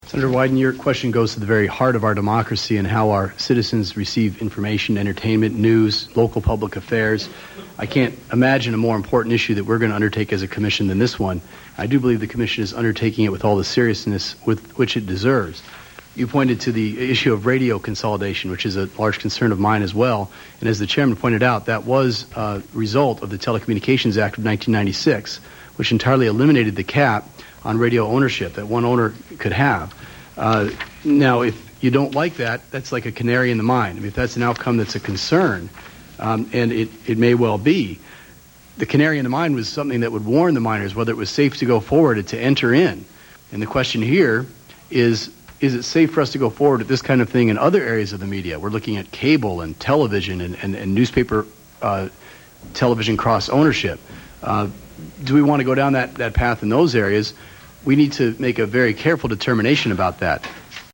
Anyway, here's a few more choice utterances from the hearing - they are also in MP3 format:
Jonathan Adelstein response to Sen. Ron Wyden [1:00, 477K]